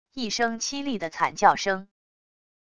一声凄厉的惨叫声wav音频